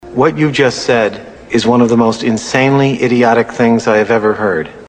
Tags: sports radio